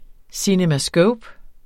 Udtale [ sinəmaˈsgɔwb ]